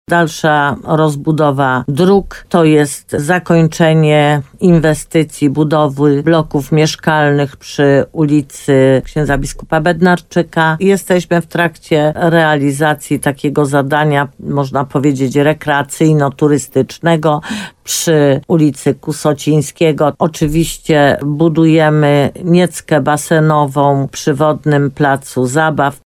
Oczywiście budujemy też nieckę basenową przy Wodnym Placu Zabaw – wymienia burmistrz Limanowej.